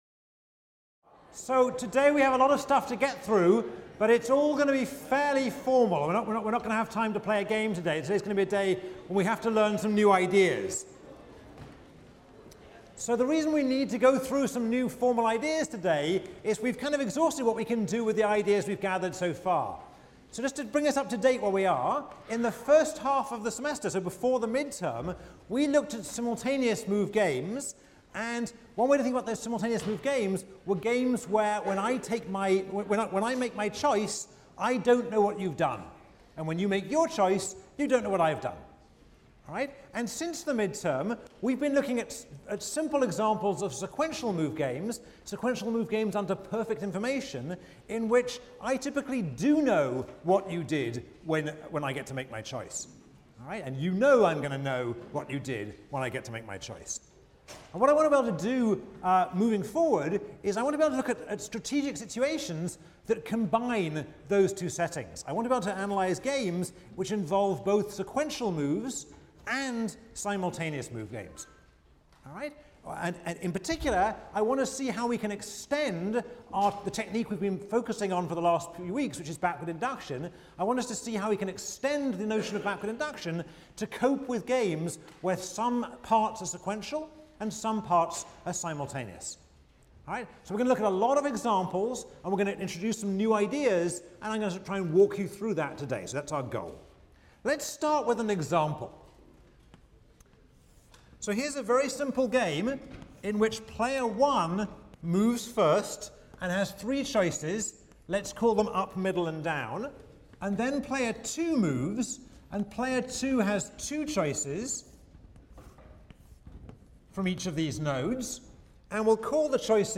ECON 159 - Lecture 18 - Imperfect Information: Information Sets and Sub-Game Perfection | Open Yale Courses